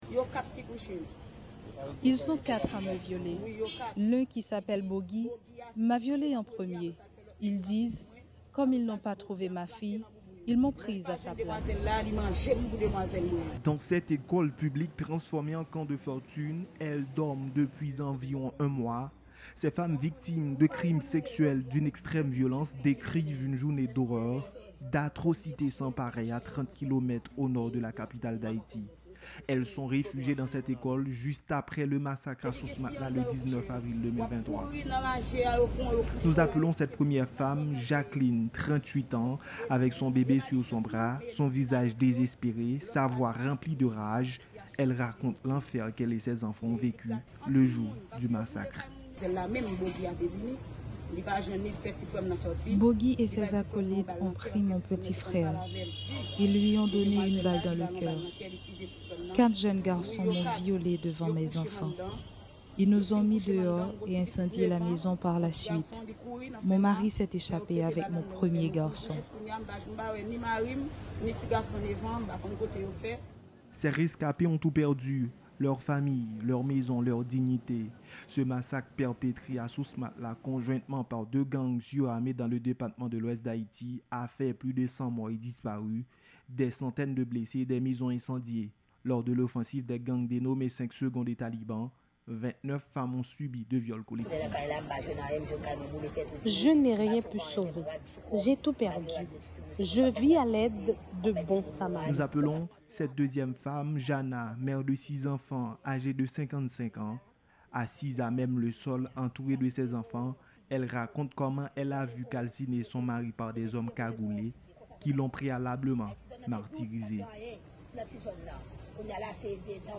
Reportage audio.